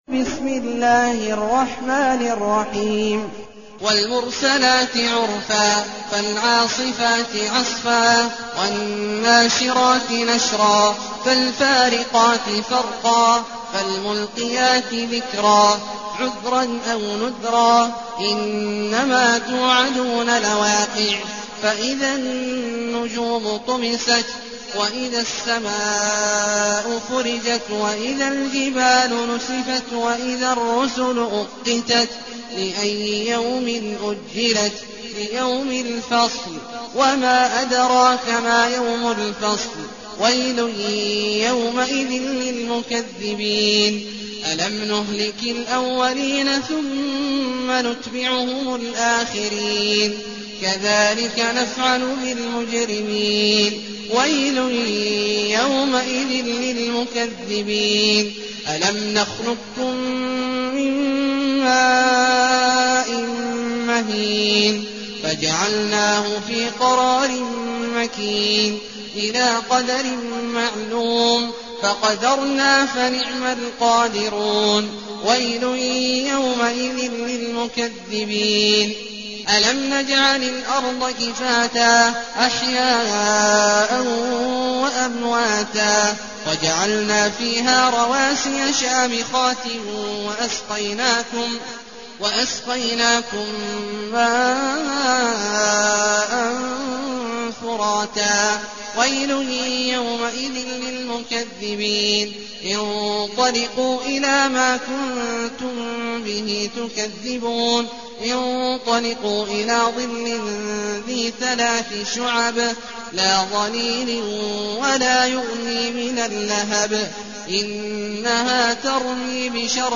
المكان: المسجد النبوي الشيخ: فضيلة الشيخ عبدالله الجهني فضيلة الشيخ عبدالله الجهني المرسلات